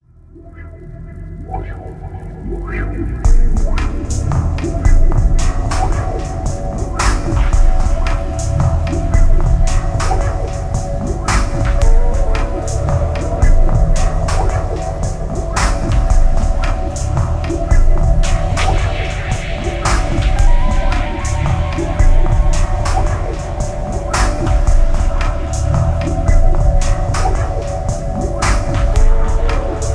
Tags: moody, cinematic